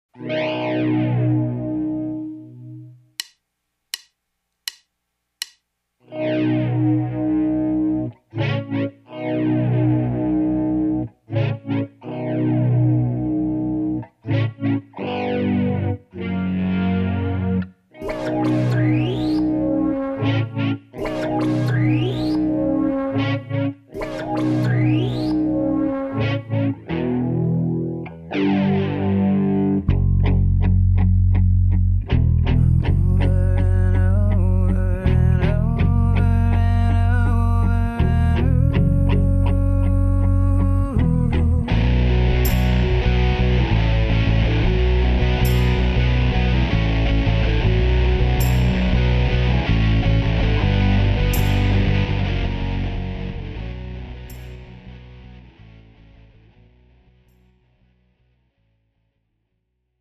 팝송) MR 반주입니다.